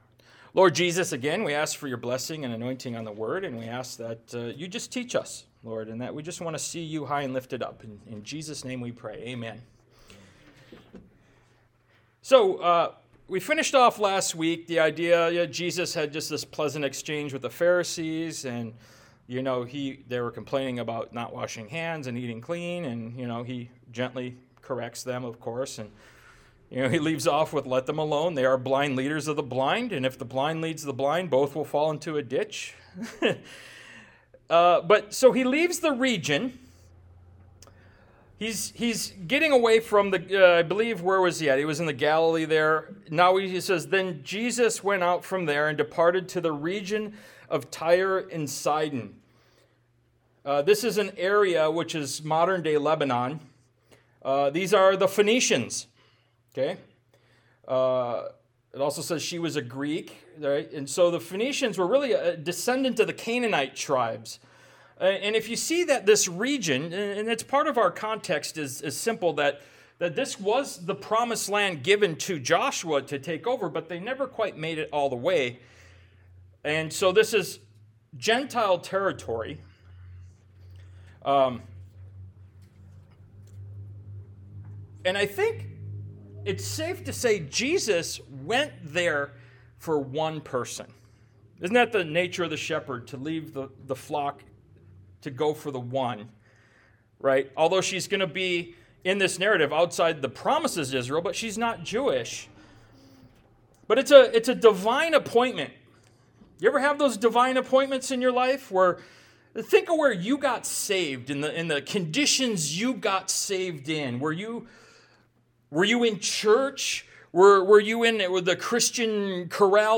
Ministry of Jesus Service Type: Sunday Morning « “Study the Bad Guys” Ministry of Jesus Part 40 “Who Do You Say I Am?”